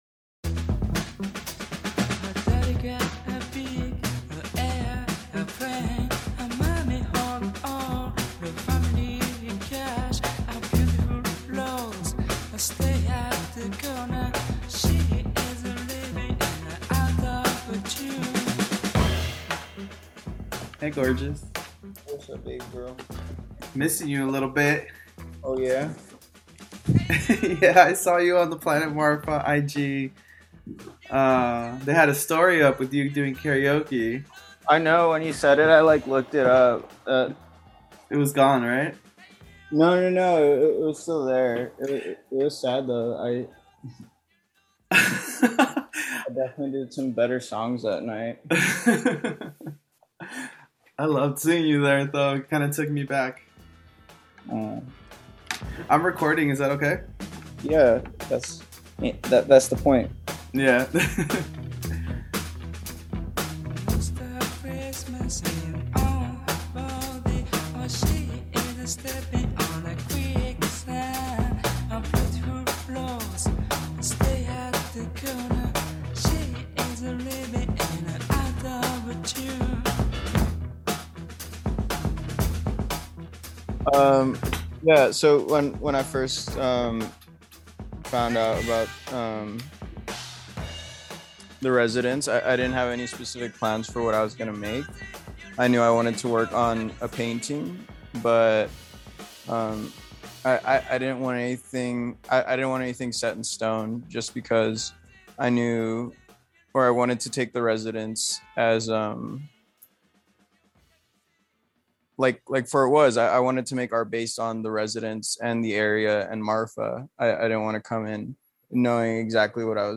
An interview from some recent Artists in Residence